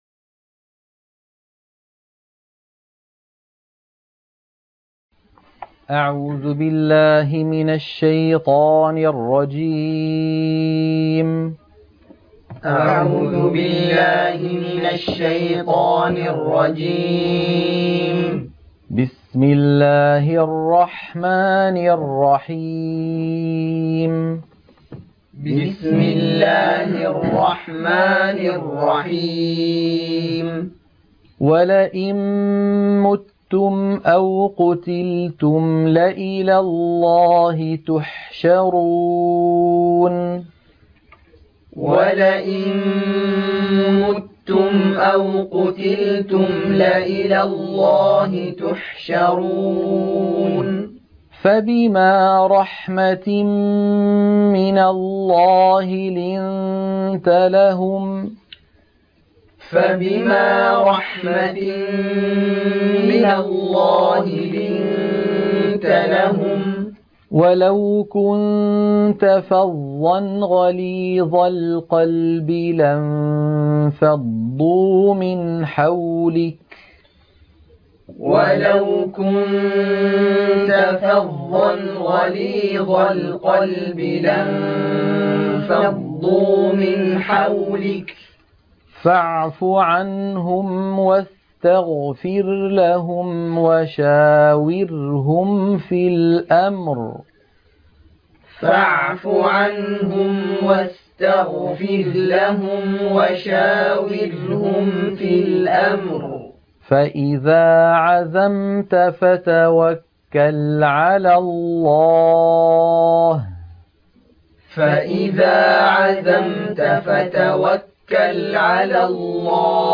عنوان المادة تلقين سورة آل عمران - الصفحة 71 التلاوة المنهجية